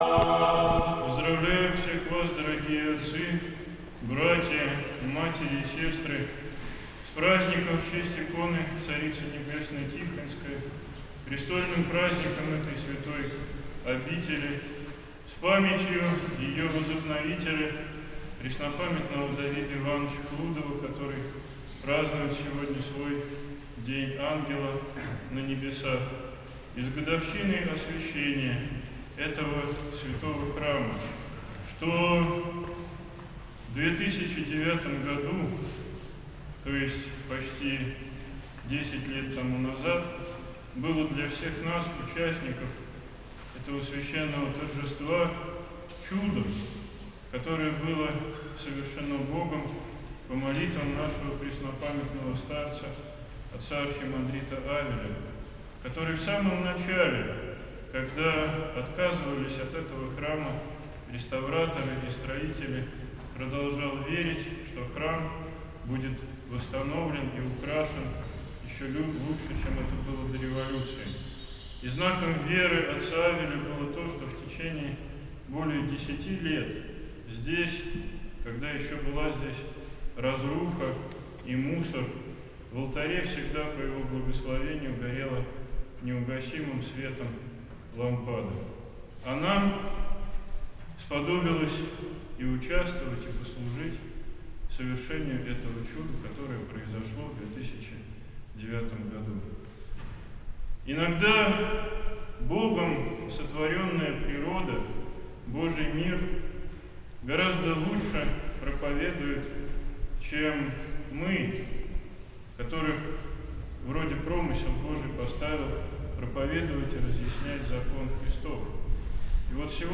Он совершил Божественную литургию, за которой ему сослужили братия монастыря в священном сане. На клиросе пел смешанный хор студентов Свято-Тихоновского Богословского Университета.